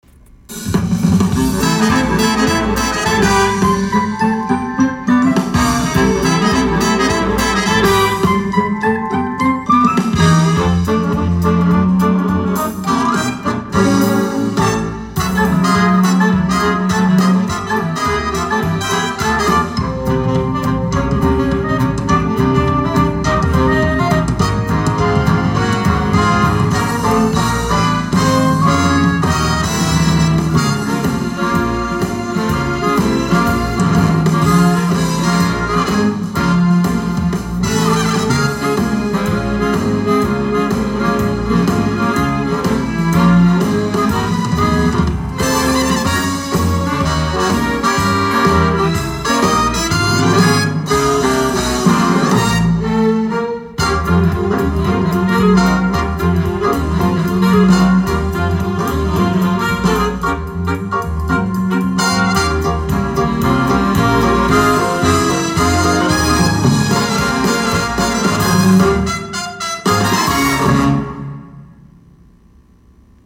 Sparklejollytwinklejingley Reprise Instrumental